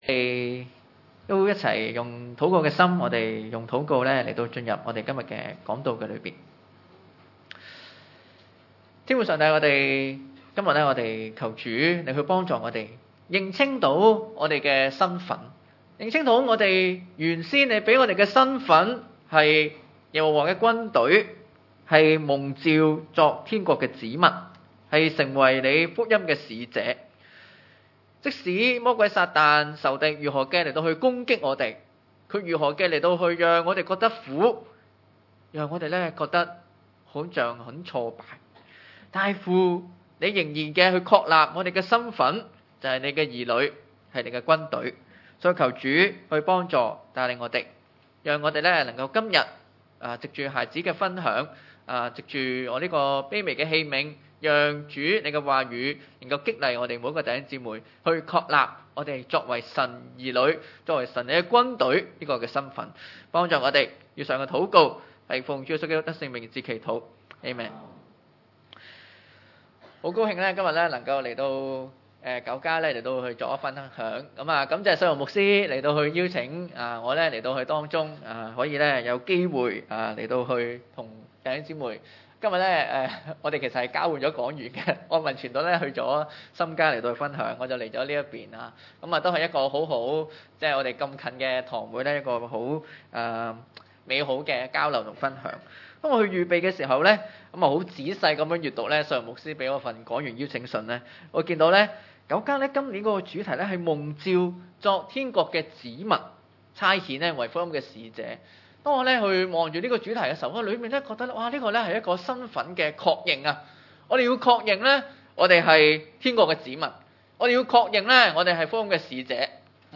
40-42 崇拜類別: 主日午堂崇拜 於是埃及人派督工的轄制他們，加重擔苦害他們。